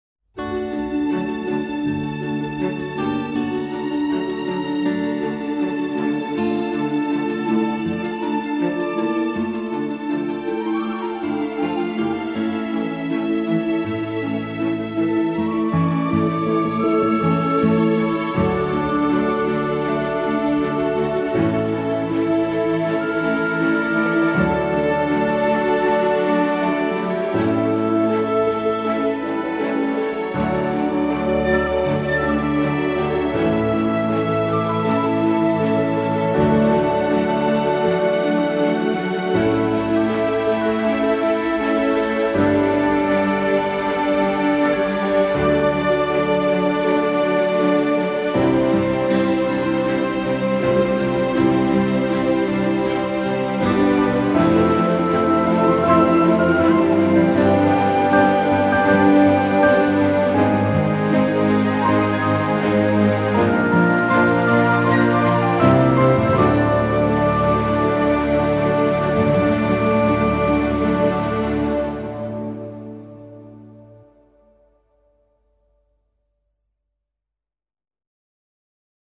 ethnique - profondeurs - epique - flute de pan - perou